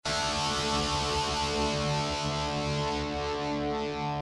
Some chords such as guitar power chords are neither major or minor because the third isn't played.
D Power chord